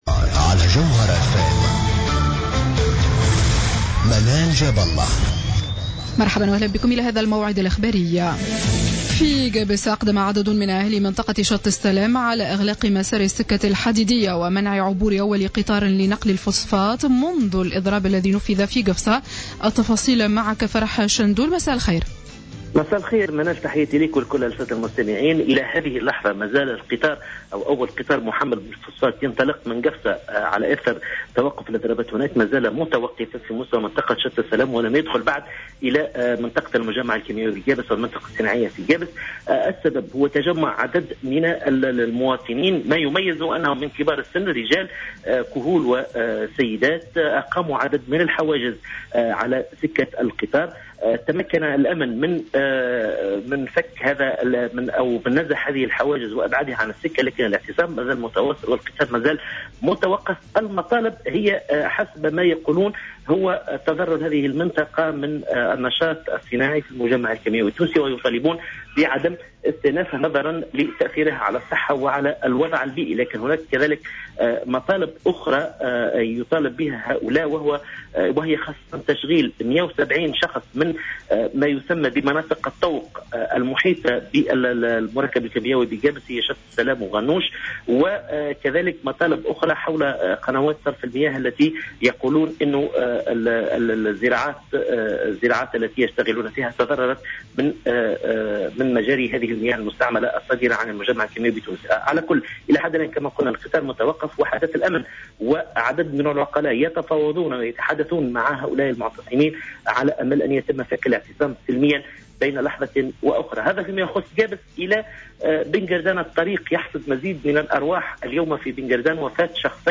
نشرة أخبار السابعة مساء ليوم الأحد 31 ماي 2015